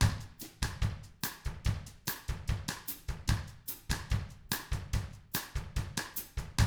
146BOSSAT1-R.wav